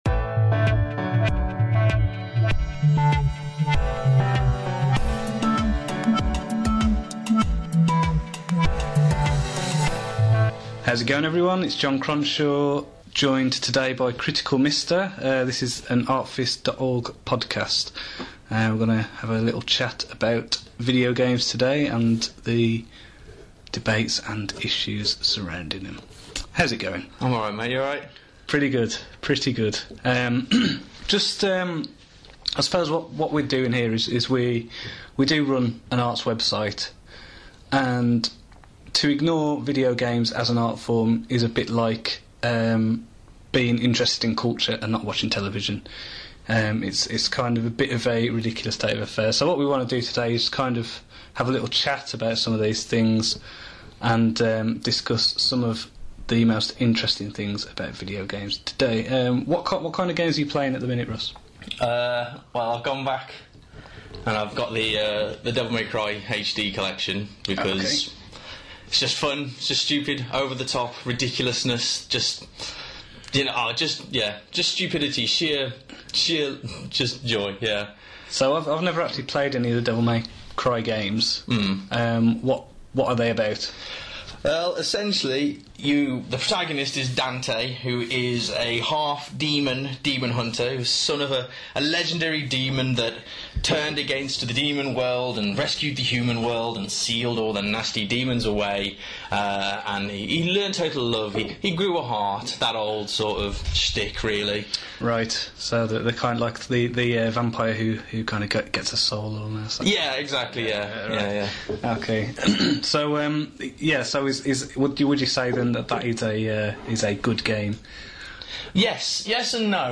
A deep discussion